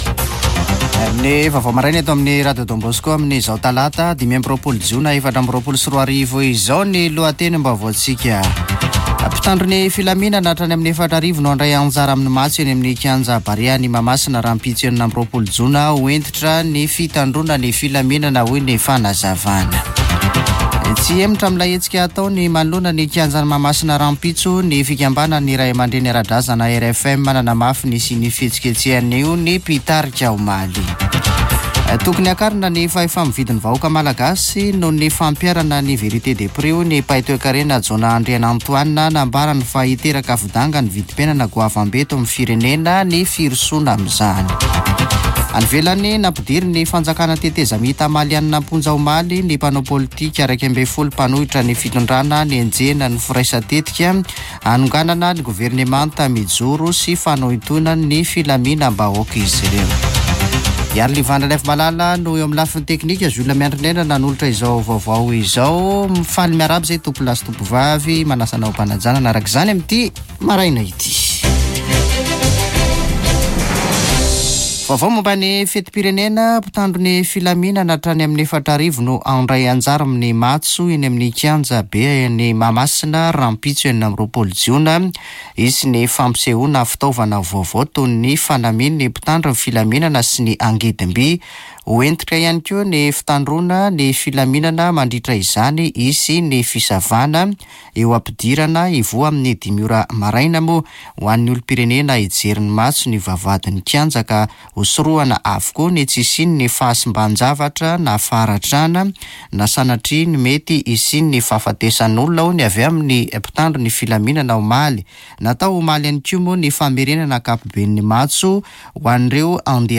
[Vaovao maraina] Talata 25 jona 2024